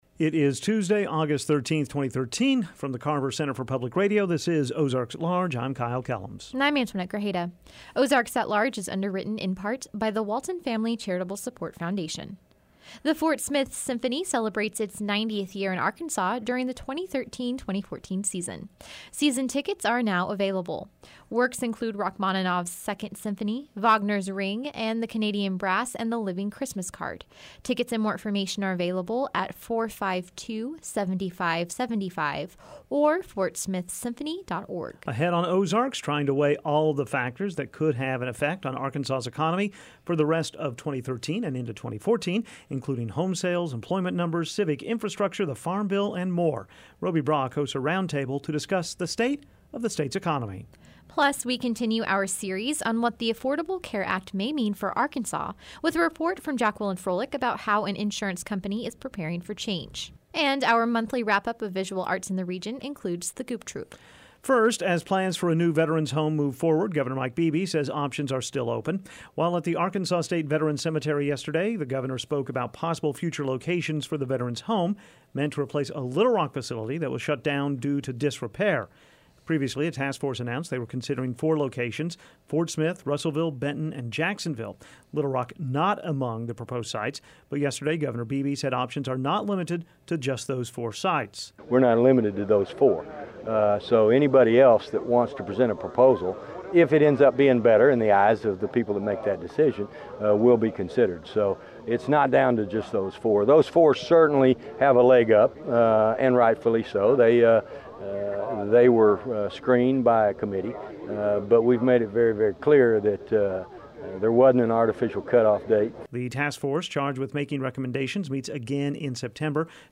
hosts a roundtable to discuss the state of the state’s economy. Plus we continue our series on what the Affordable Care Act may mean for Arkansas with a report about how an insurance company is preparing for change.